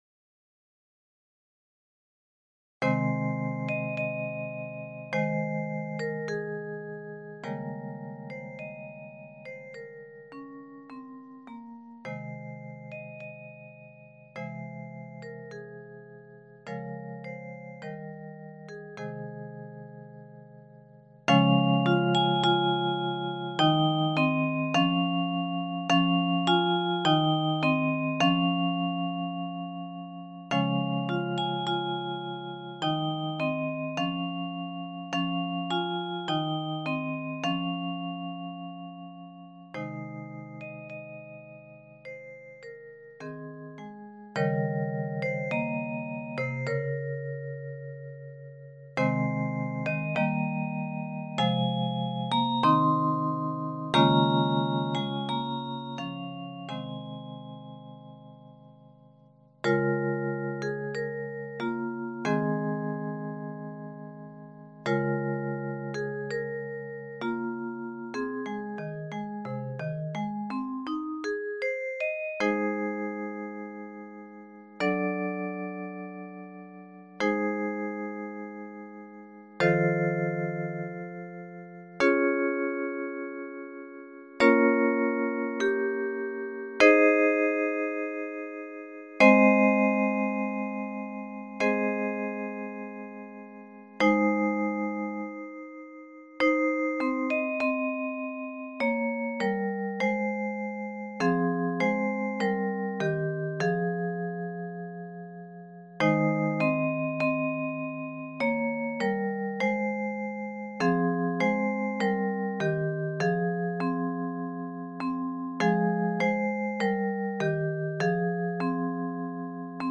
Key of Ab Major.